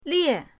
lie4.mp3